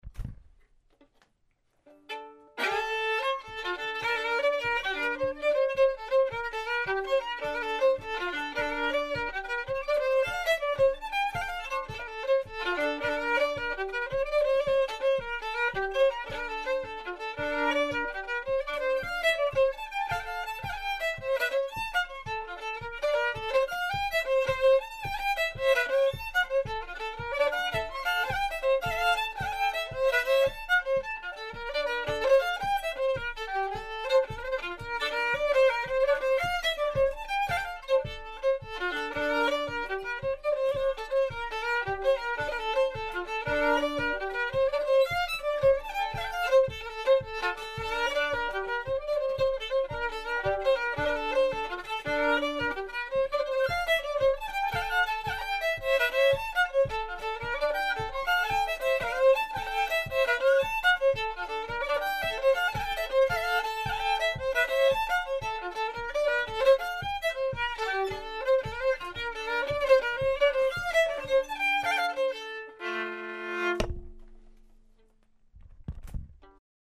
Joe Peters Jig - jig
solo fiddle - reference recording